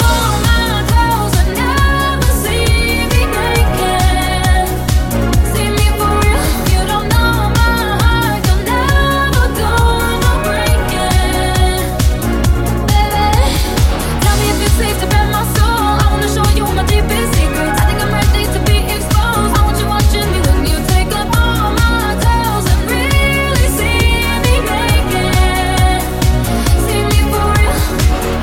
Genere: pop,house, deep, club, remix